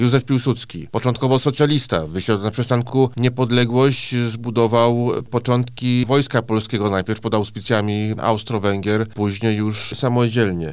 O tym, z czego szczególnie zasłynął nasz bohater, mówi zastępca prezesa IPN dr Mateusz Szpytma: